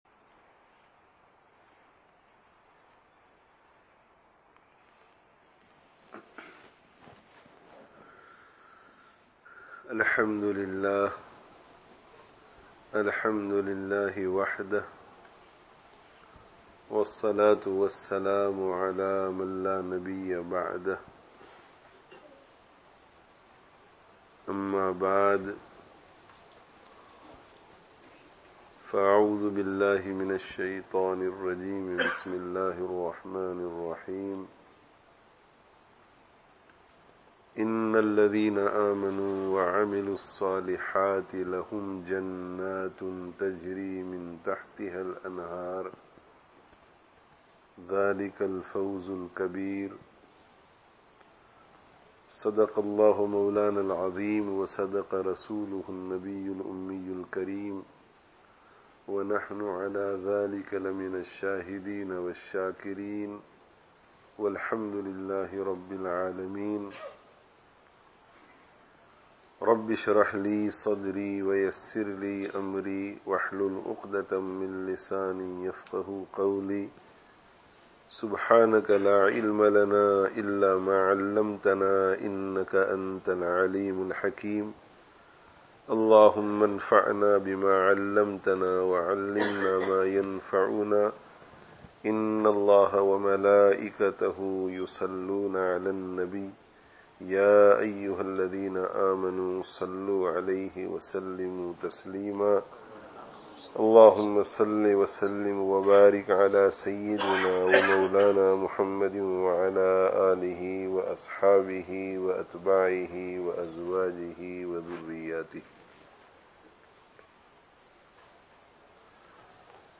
Īmān, 'Amal awr 'Ilm (Madani Education Centre, Leicester 03/09/14)